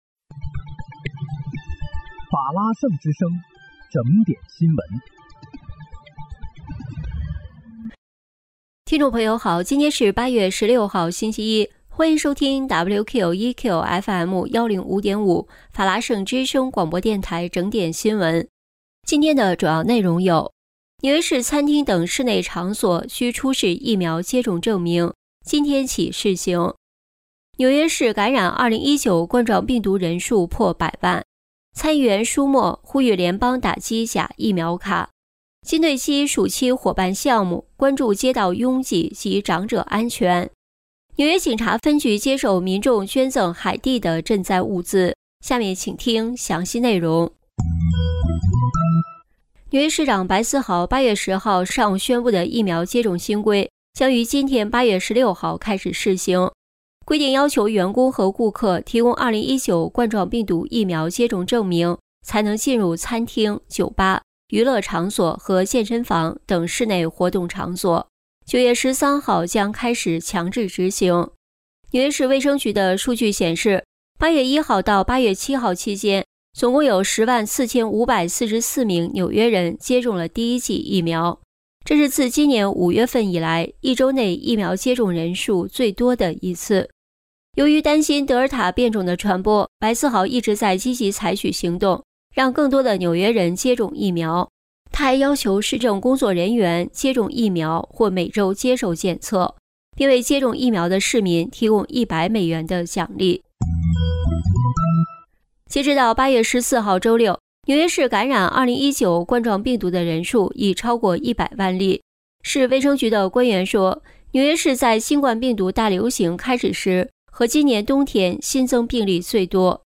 8月16日（星期一）纽约整点新闻